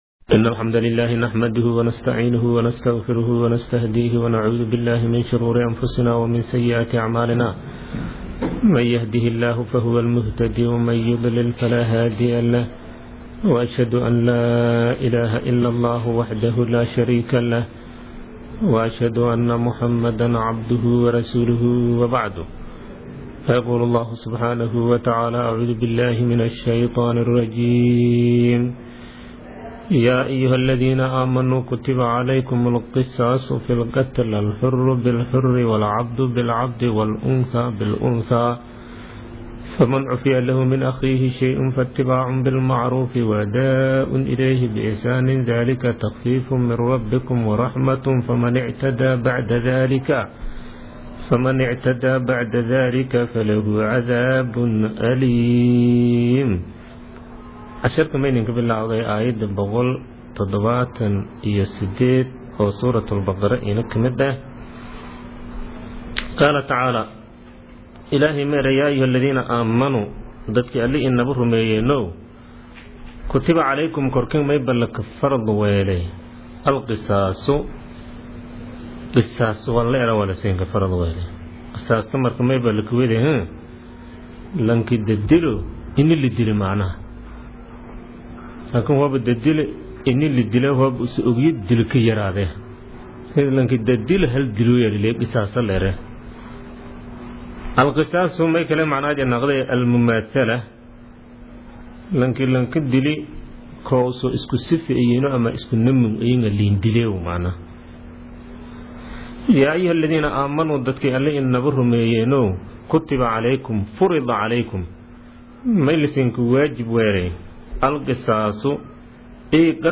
Casharka Tafsiirka Maay 22aad